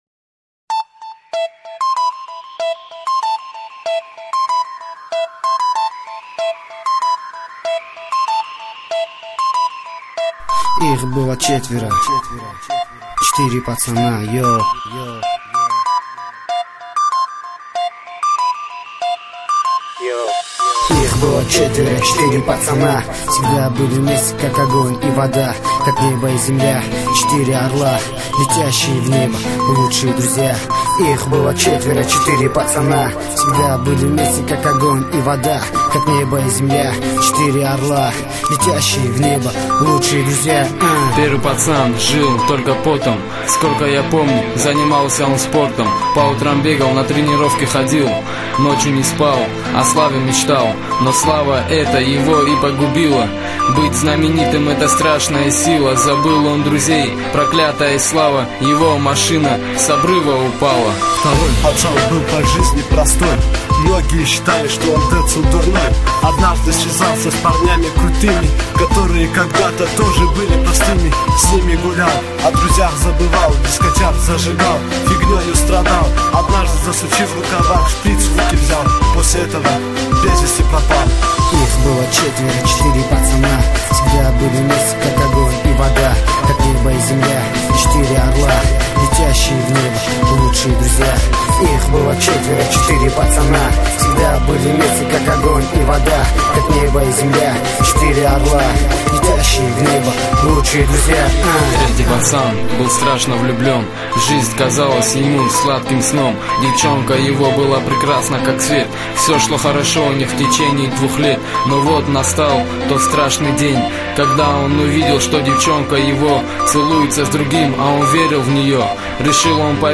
Категория: Пацанский рэп